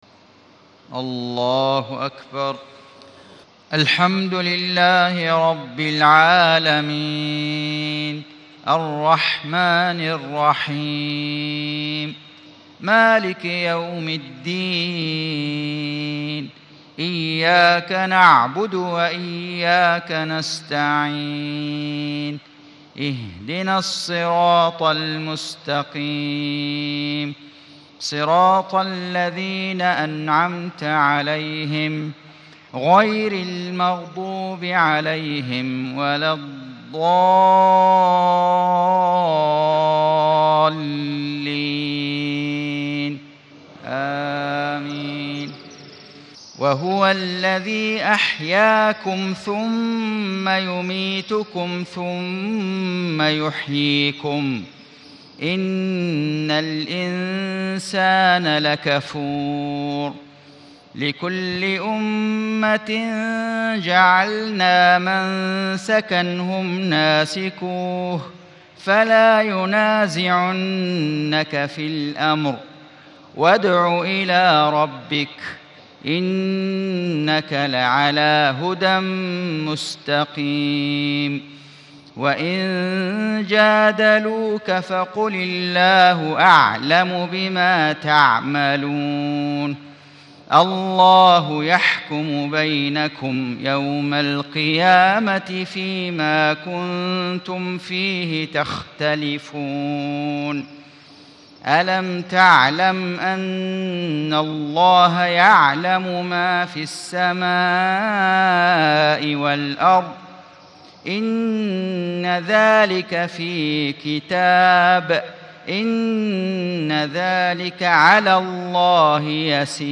صلاة المغرب 1 - 3 - 1436 تلاوة من سورة الحج .